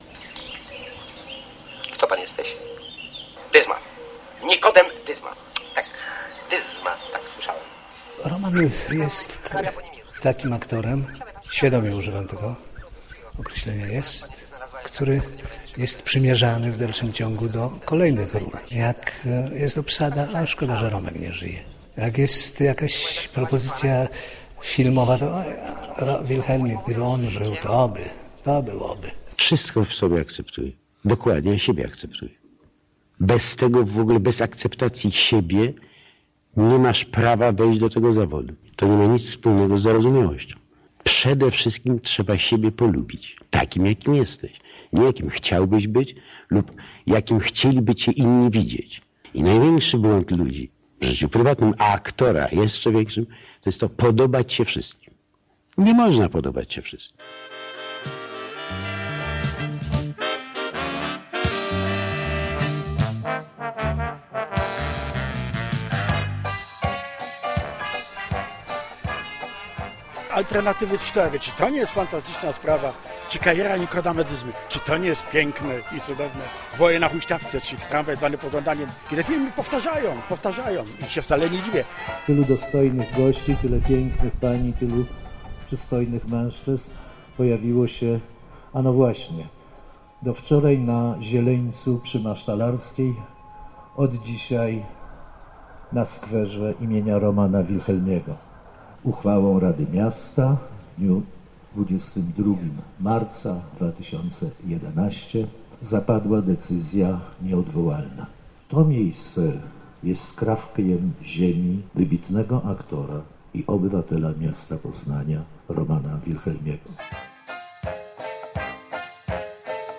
Portret Romana - reportaż